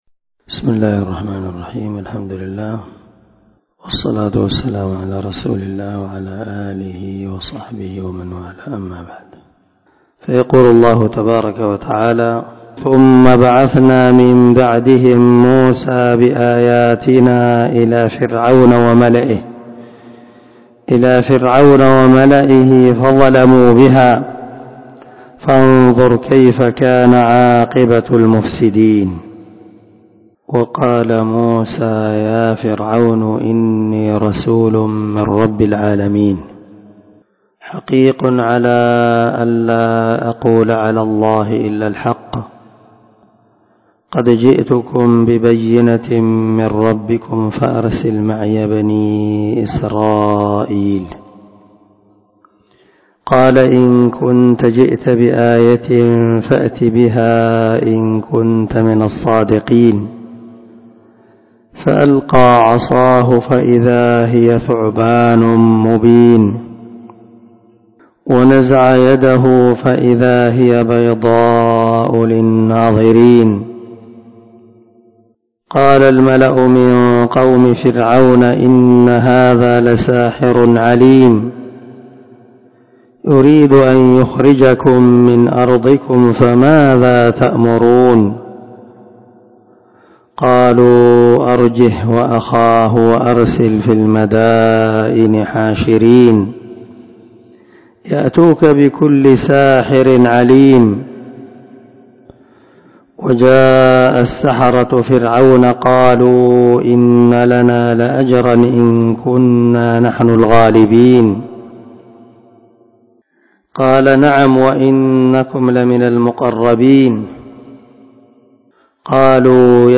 480الدرس 32 تفسير آية ( 117 – 126 ) من سورة الأعراف من تفسير القران الكريم مع قراءة لتفسير السعدي